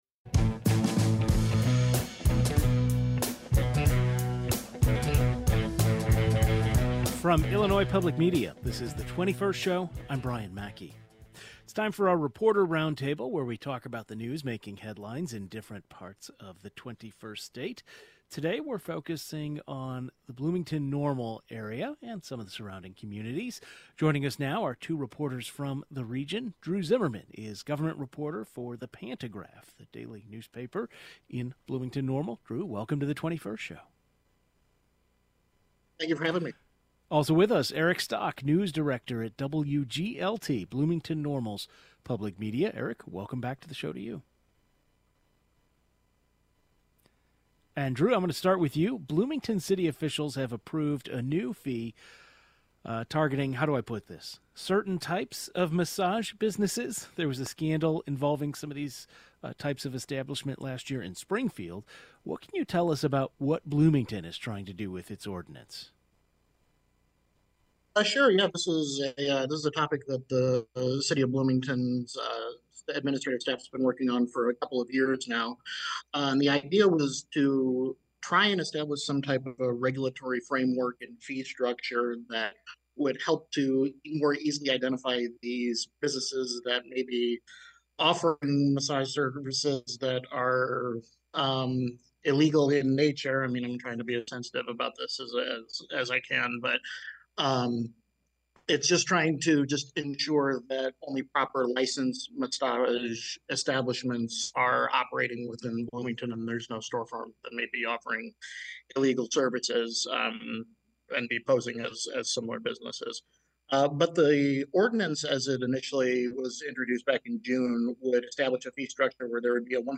During our Friday reporter roundtable, we're talking about stories making headlines in Bloomington-Normal, including how Illinois State University is planning to directly pay its athletes and a major settlement involving the McLean County Jail.